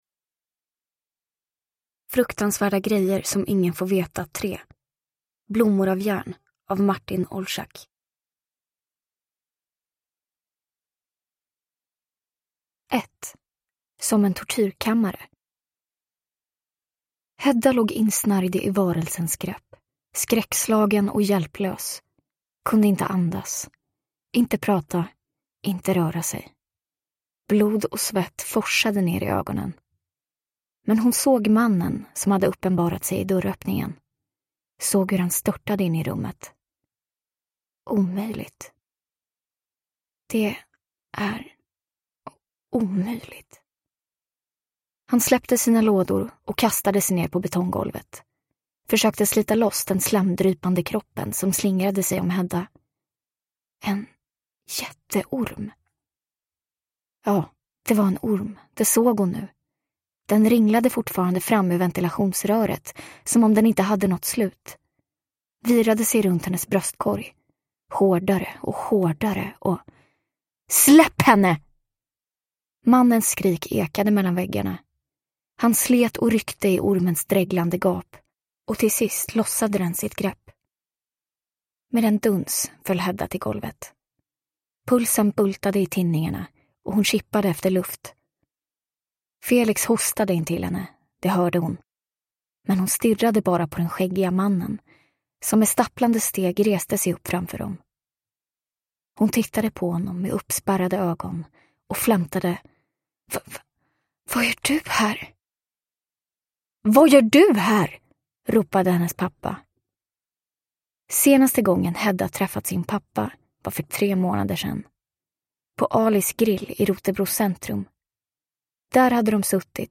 Blommor av järn (ljudbok) av Martin Olczak